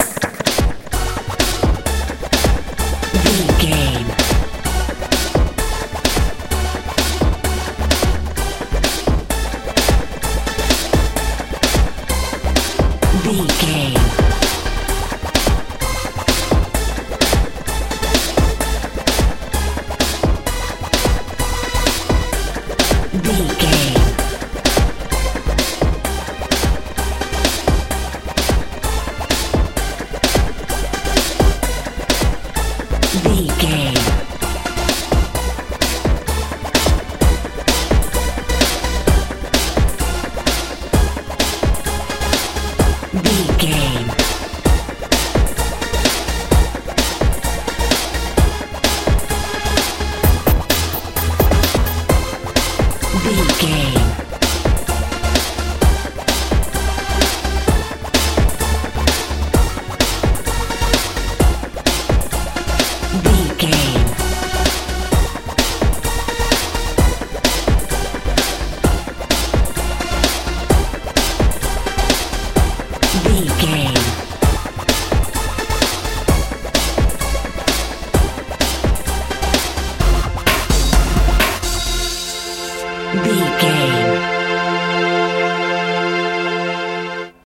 euro dance
Aeolian/Minor
F♯
strange
futuristic
synthesiser
bass guitar
drums
80s
suspense
tension